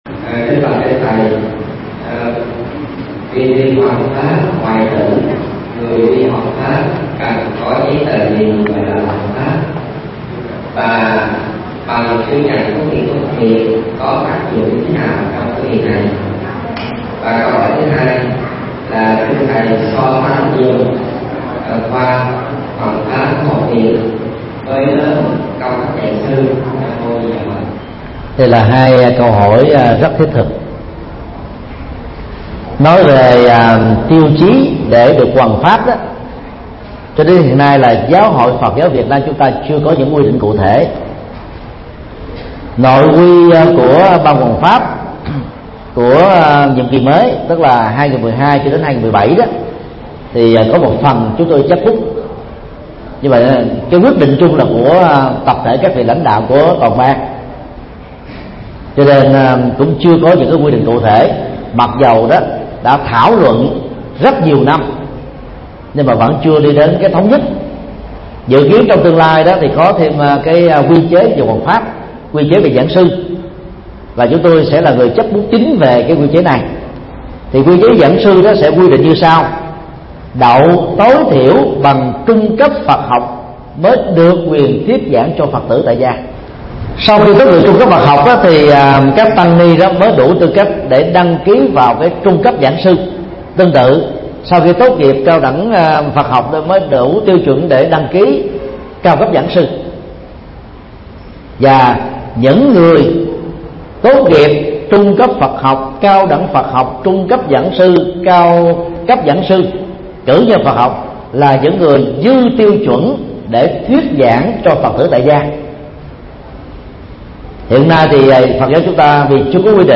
Vấn đáp: Tiêu chí để được hoằng pháp – Thầy Thích Nhật Từ mp3